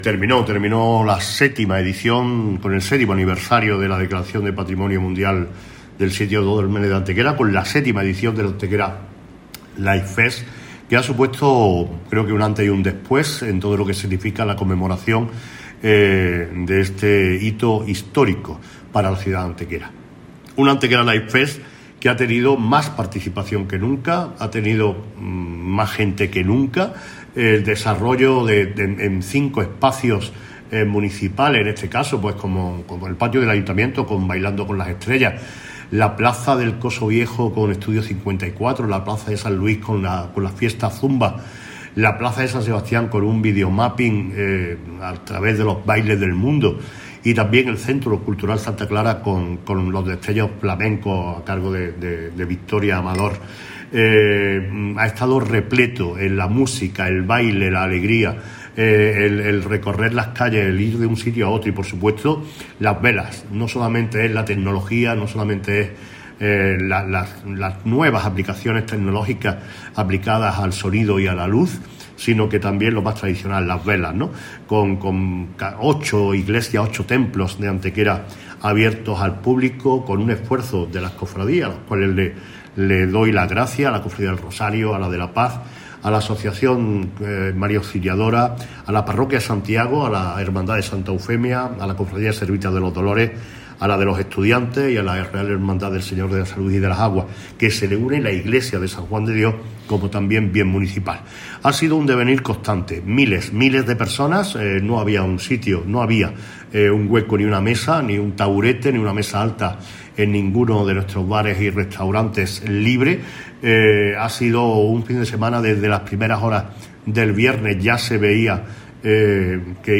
Valoración del Alcalde
Cortes de voz